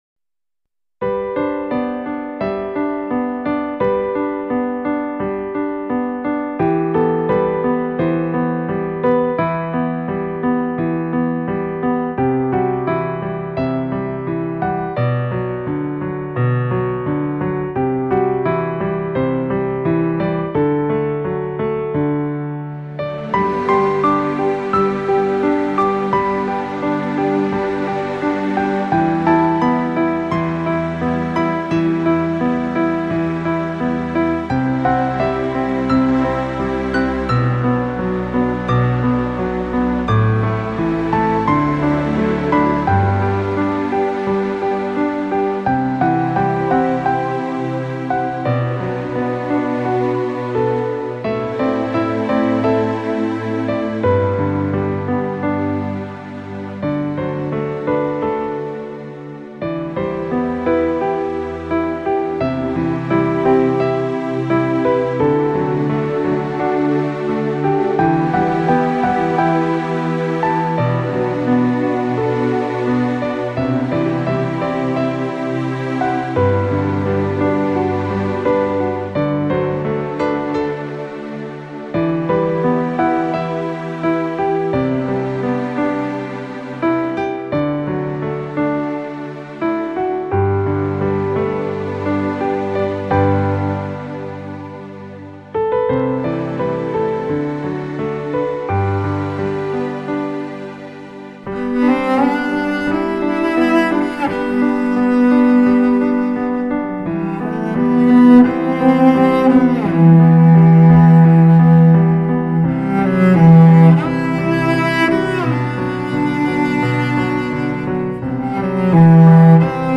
浪漫新世纪音乐
大提琴